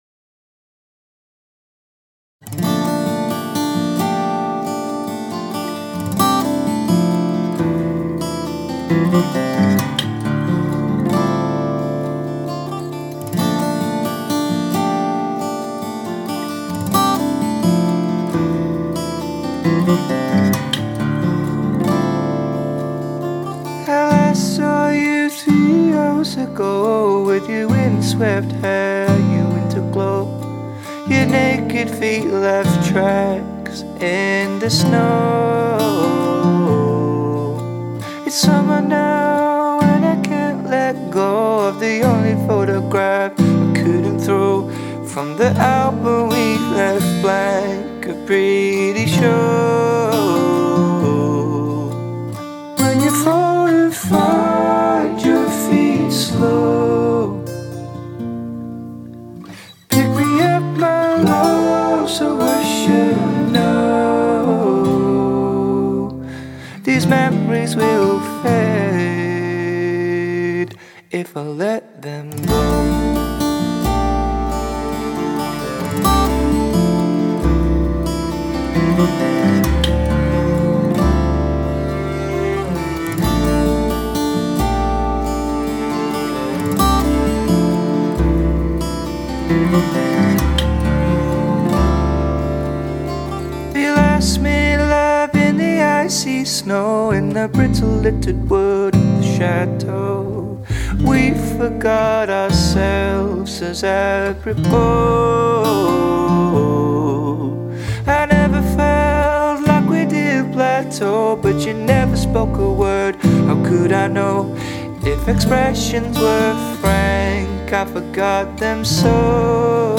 I recorded, mixed and mastered the track, as well as shooting and editing the video myself.
Guitar, Vocals
Double Bass